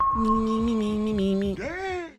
Play djkNotification, Download and Share now on SoundBoardGuy!
djknotification.mp3